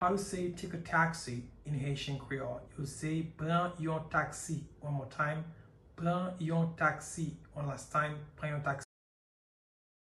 Listen to and watch “Pran yon taksi” pronunciation in Haitian Creole by a native Haitian  in the video below:
Take-a-taxi-in-Haitian-Creole-Pran-yon-taksi-pronunciation-by-a-Haitian-teacher.mp3